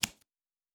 Cards Place 07.wav